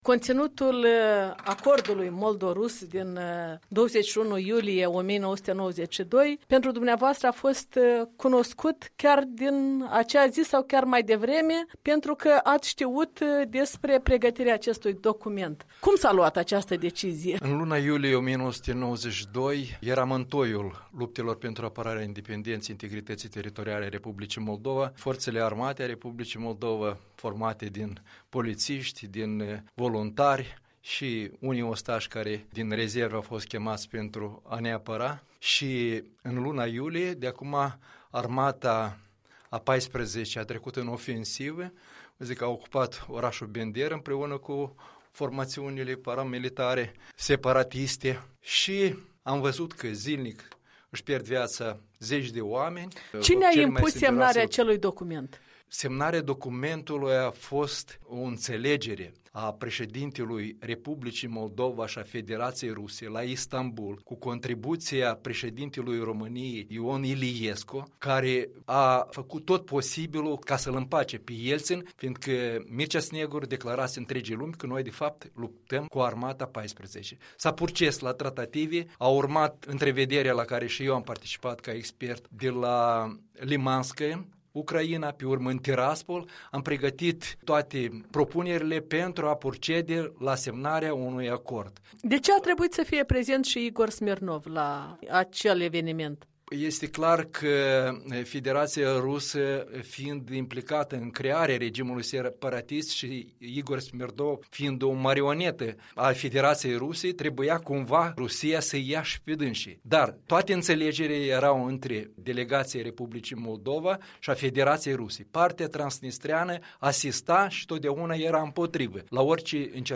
La 25 de ani de la semnarea Convenției moldo-rusă de reglementare pașnică a conflictului din regiunea transnistreană, un interviu cu un martor al timpului.